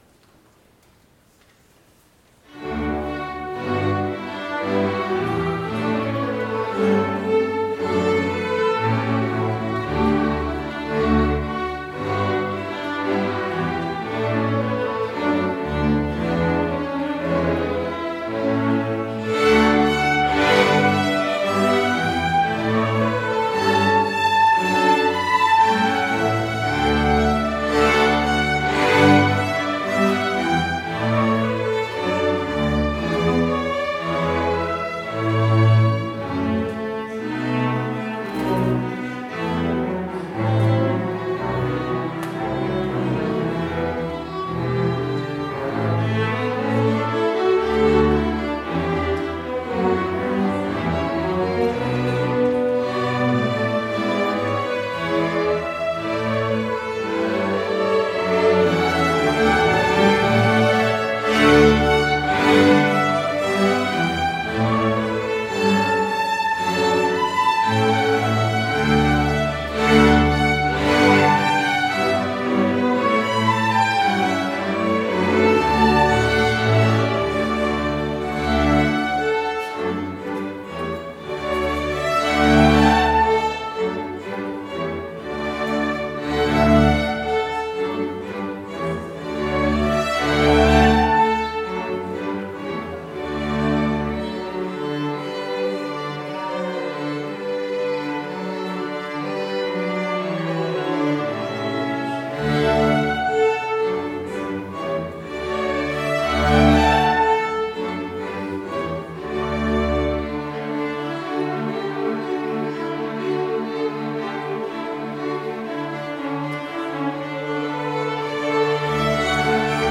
Complete service audio for Chapel - January 28, 2022